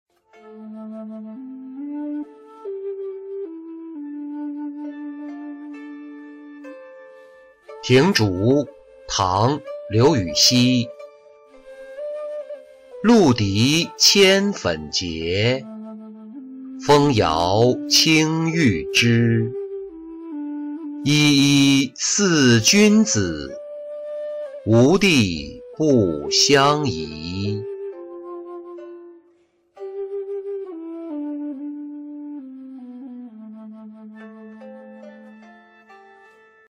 庭竹-音频朗读